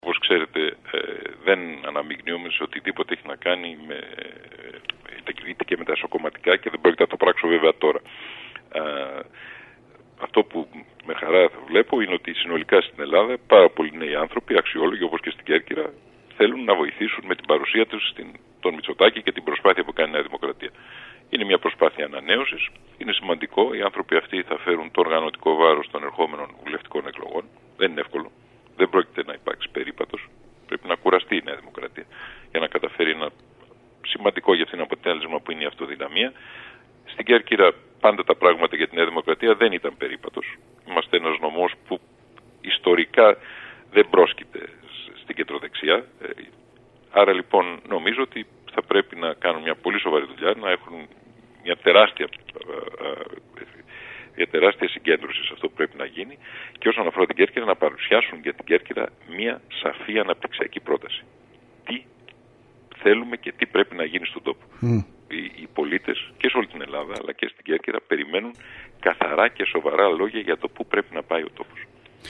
Αναφορά στις εσωκομματικές εκλογές της ΝΔ έκανε, μιλώντας στην ΕΡΤ Κέρκυρας και ο κοινοβουλευτικός εκπρόσωπος τη ΝΔ Νίκος Δένδιας, τονίζοντας ότι τα όργανα που θα προκύψουν, θα κληθούν να διαχειριστούν ένα δύσκολο έργο τριών εκλογικών αναμετρήσεων.